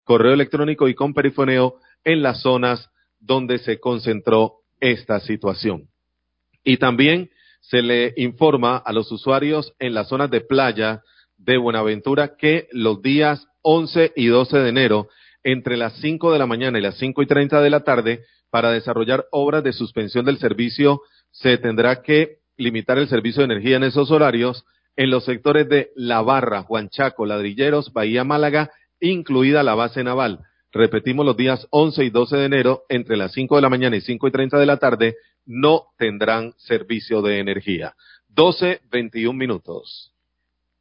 comunicado de prensa